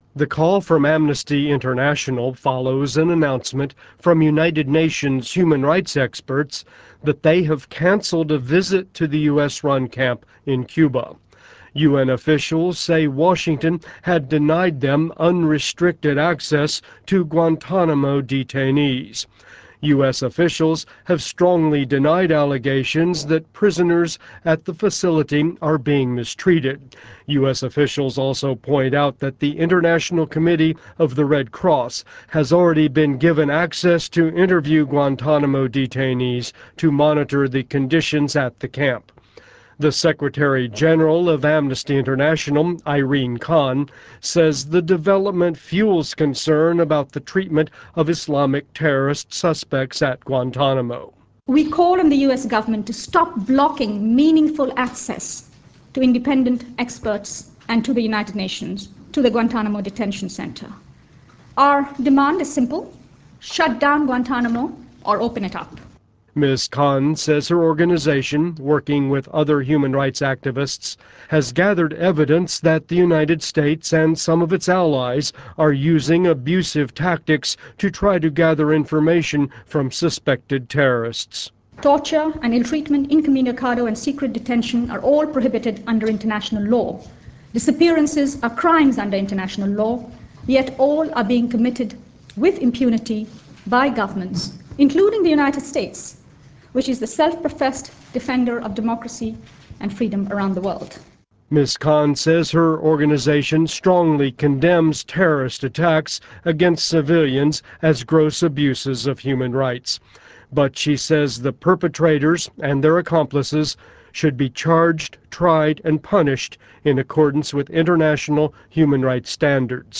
VOA News - audio activity